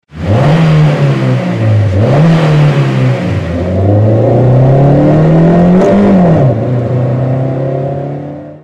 Vielleicht ist die "tuned exhaust note" nur in den USA umgesetzt worden.
That’s not the case with the CR-Z. Despite the car’s hybrid chops it growls and revs more like a sports coupe than an “eco-mobile.” The CR-Z’s tuned exhaust note is something our engineers purposely developed and obsessively perfected.
While you can’t test drive the CR-Z and hear it purr from behind the wheel quite yet, you can have the throaty exhaust announce your next phone call, with the new CR-Z ring tone.